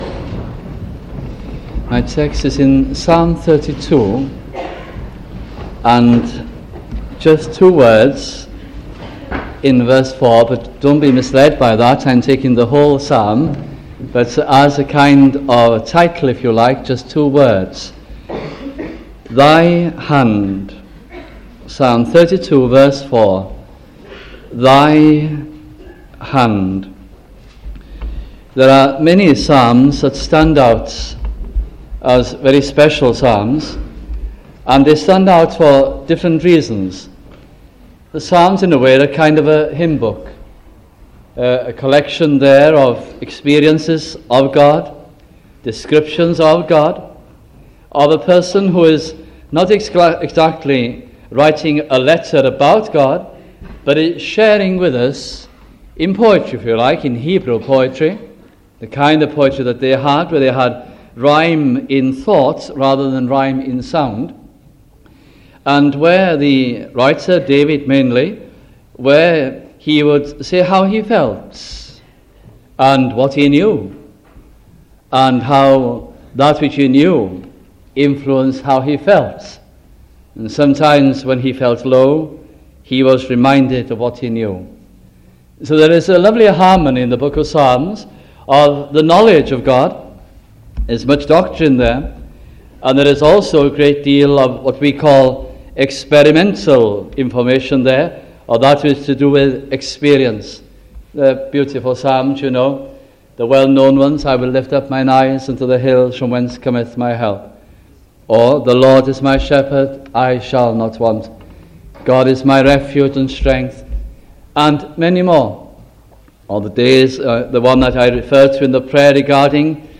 » Psalms Gospel Sermons